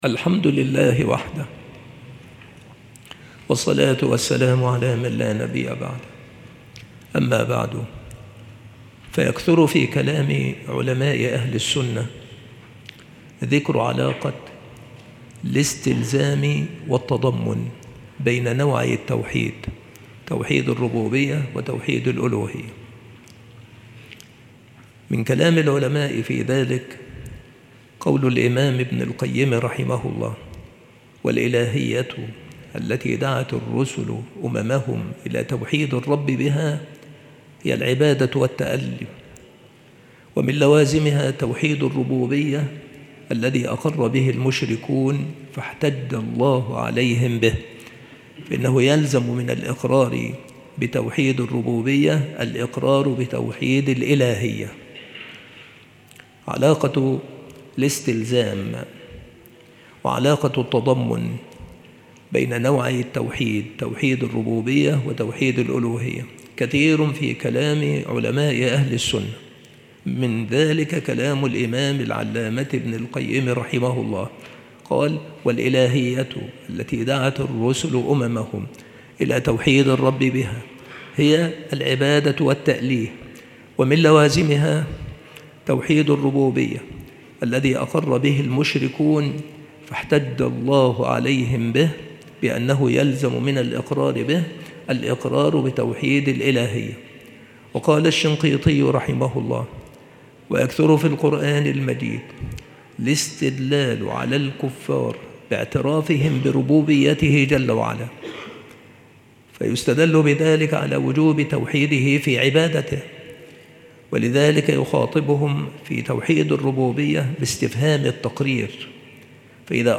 السلسلة مواعظ وتذكير
مكان إلقاء هذه المحاضرة بالمسجد الشرقي - سبك الأحد - أشمون - محافظة المنوفية - مصر